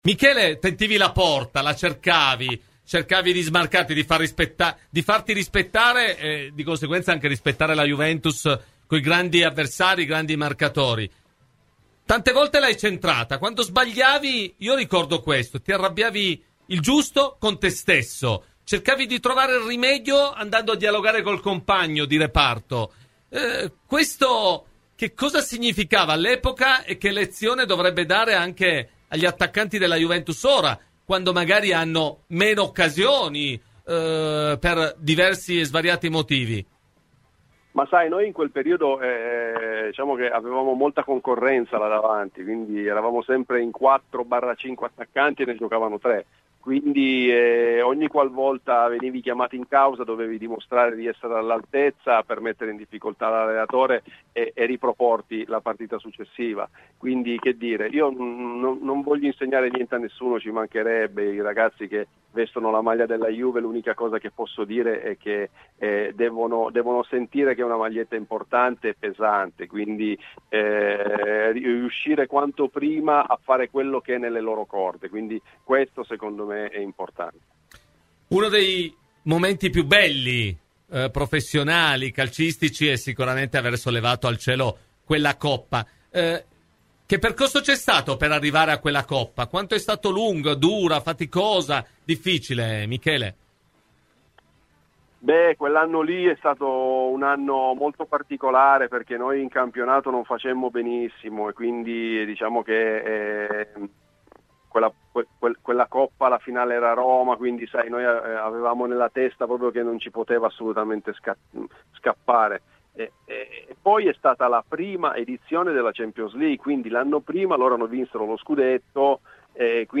Michele Padovano è stato il super ospite della puntata di " Cose di Calcio XL " su Radio Bianconera .